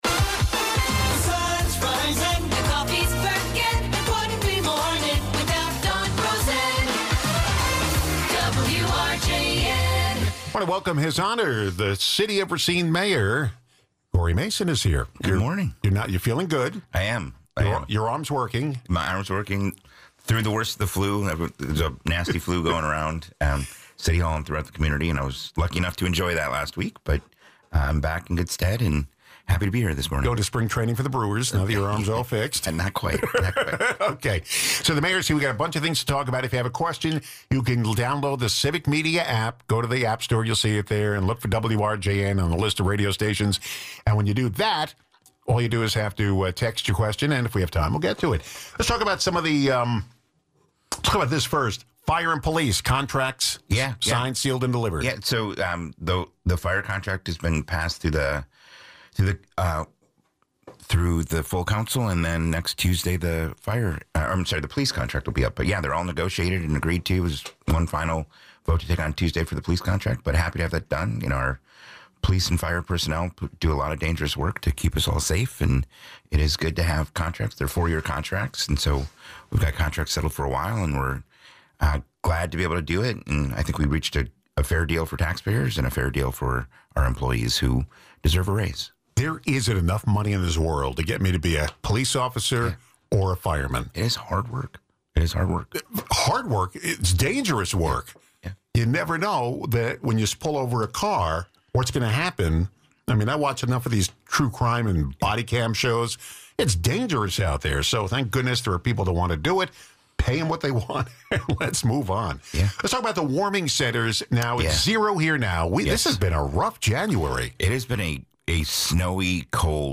City of Racine Mayor Cory Mason, in his monthly visit to WRJN, discusses issues of interest to city residents.